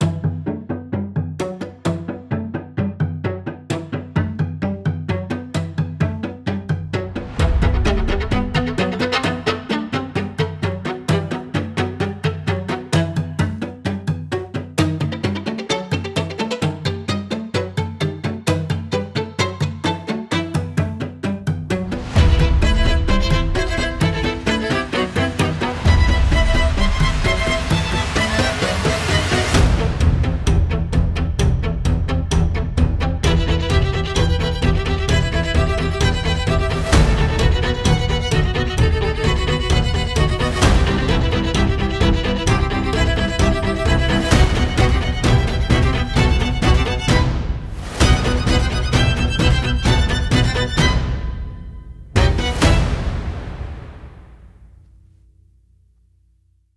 Tense and atmospheric strings
• Raw, gritty, perfectly imperfect solo string articulations
• Great for aggressive horror and tense music
Raw and gritty